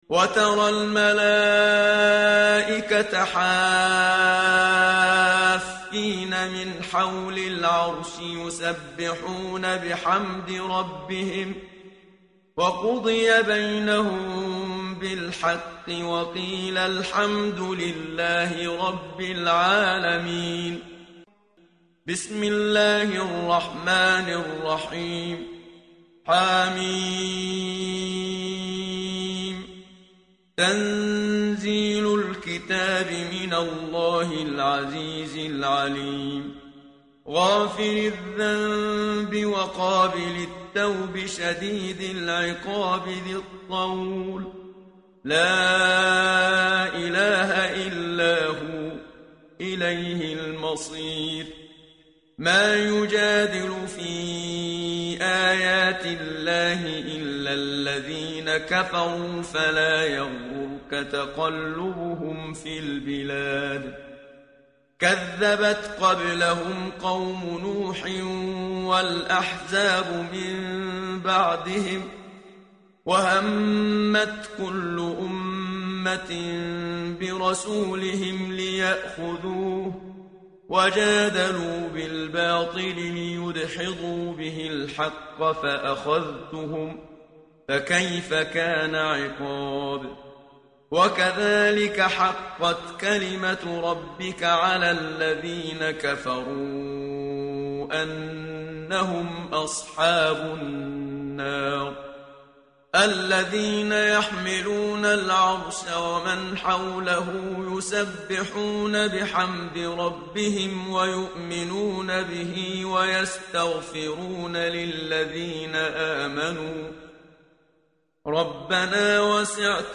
متن قرآن همراه باتلاوت قرآن و ترجمه
tartil_menshavi_page_467.mp3